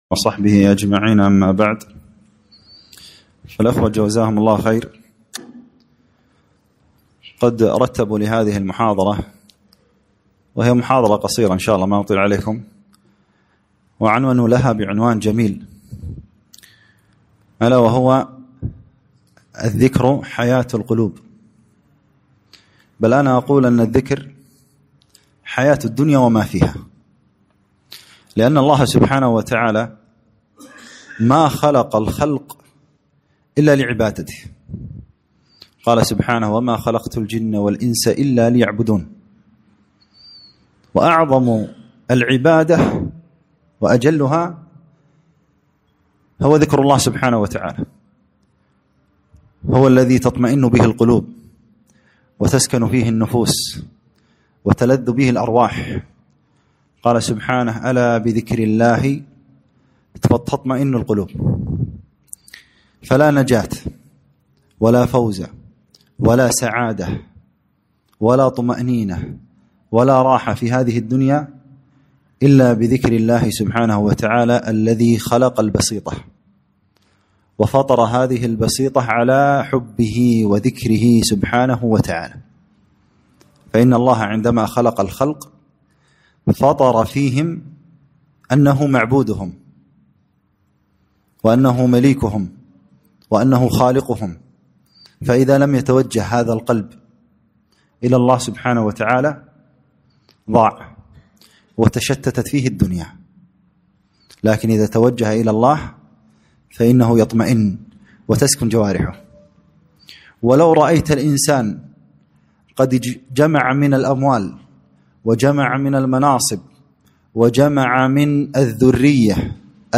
محاضرة - الذكر حياة القلوب